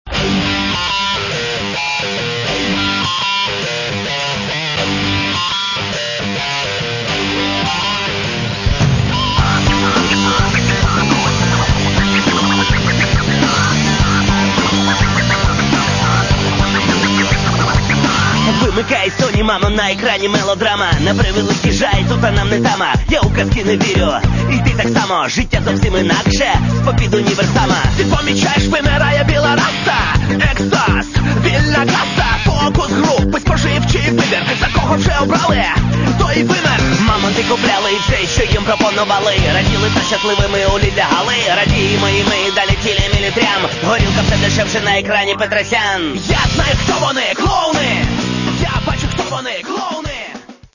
Каталог -> Рок та альтернатива -> Енергійний рок